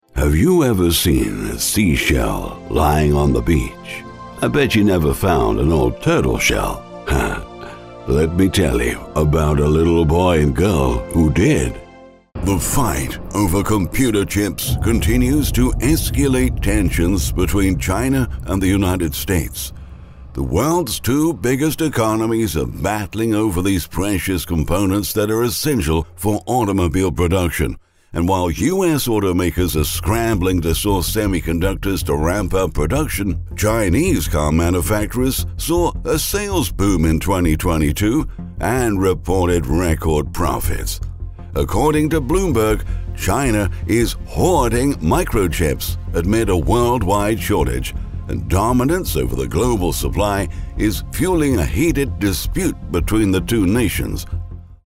Anglais (britannique)
Ma voix est profonde mais claire.
Gravité
Amical
Chaleureux